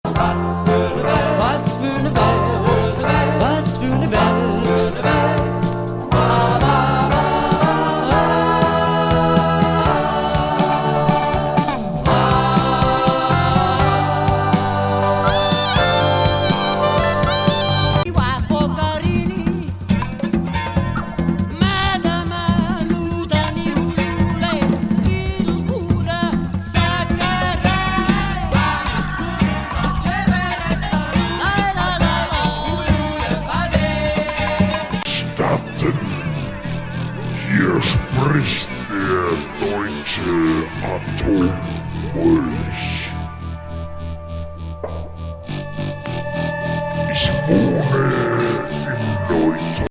クロスオーバー・サイケな
密林グルーブ
回転遅呪術声のドサイケ